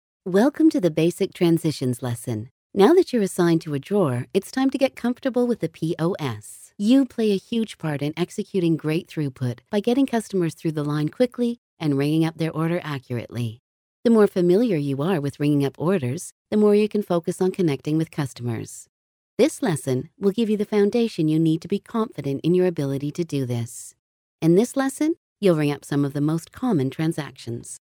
She can "do" sexy, soft sell effortlessly, but she can also do corporate, conversational and voices for animation.
Sprechprobe: eLearning (Muttersprache):